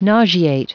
Prononciation du mot nauseate en anglais (fichier audio)
Prononciation du mot : nauseate